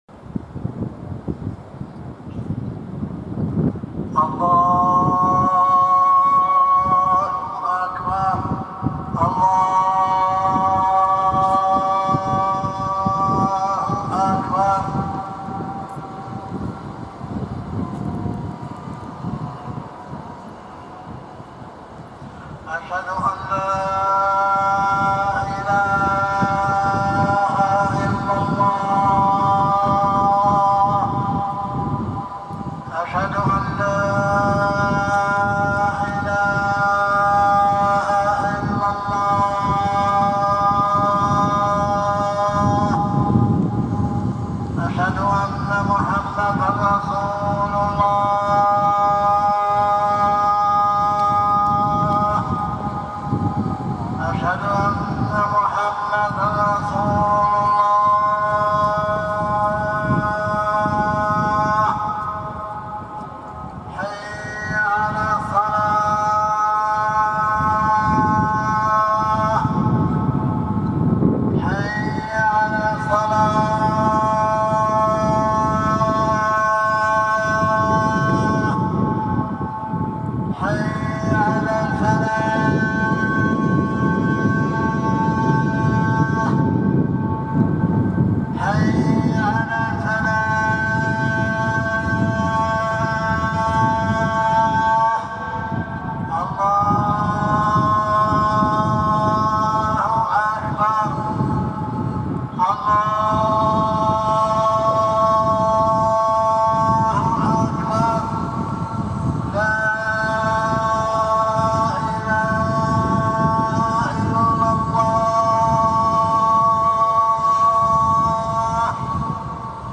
Adhaan أذان Gebetesruf zum Ritualgebet
Adhaan, so wie er in verschieden Orten mit oder ohne Lautsprecher zu h�ren ist.